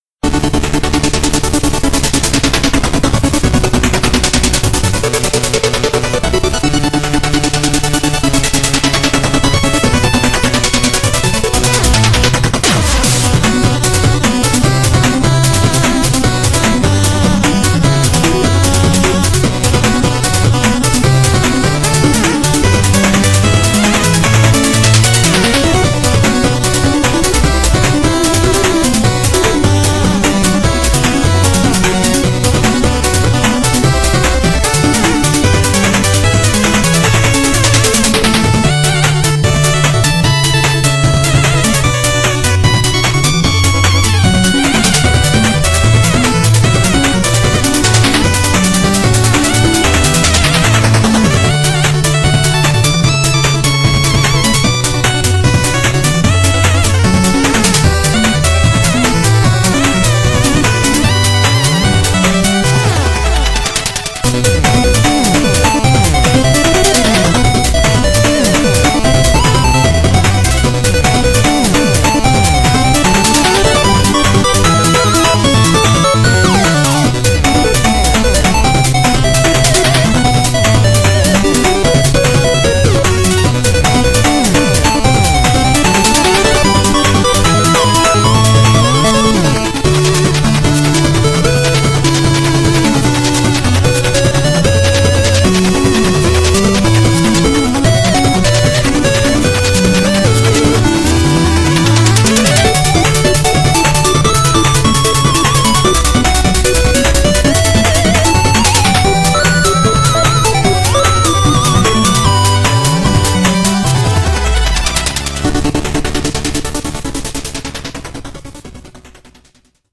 BPM150
Audio QualityPerfect (Low Quality)